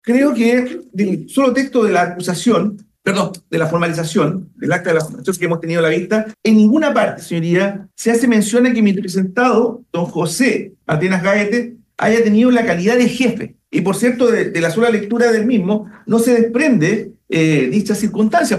En la audiencia de formalización de cargos, el Ministerio Público entregó detalles de la acusación en contra de los cuatro nuevos detenidos por su participación en el megaincendio que se registró el 2 y 3 de febrero en la región de Valparaíso, cuya emergencia dejó 136 fallecidos.